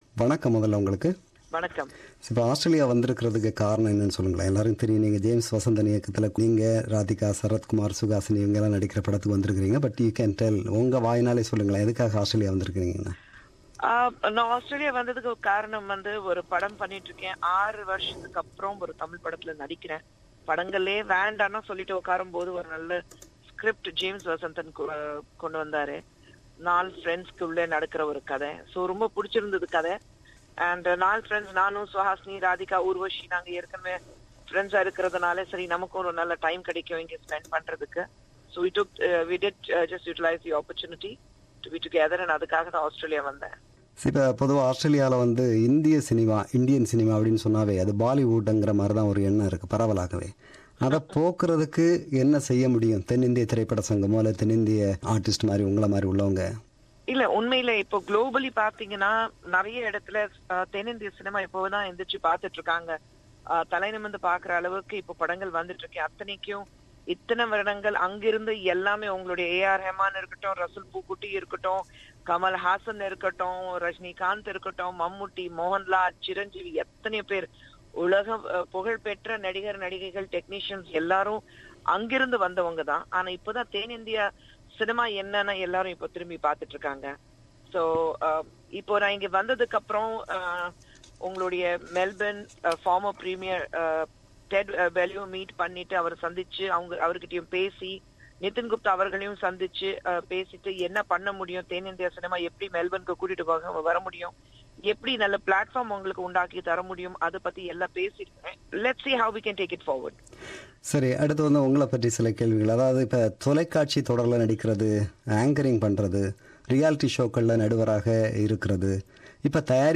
Interview with Kushboo – Part 1